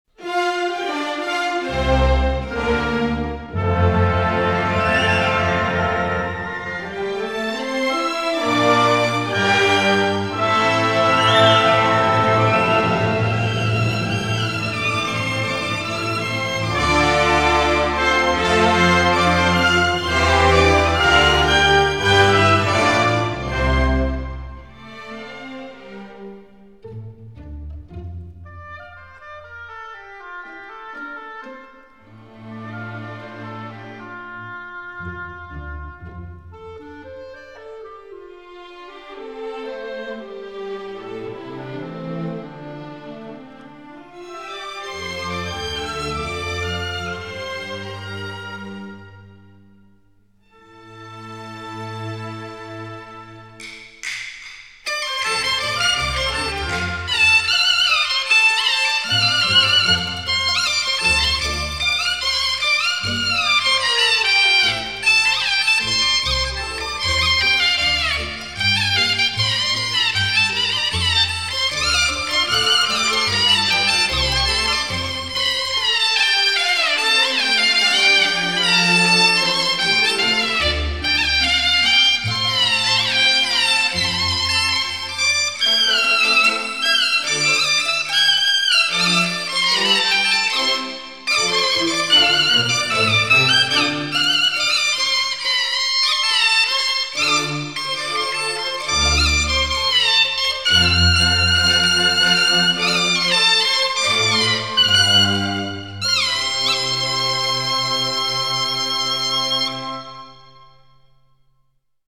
全部曲目充分结合了管弦乐器和民族乐器，使得音乐本身即更加立体感，又不失民族本色
音乐类型: 民乐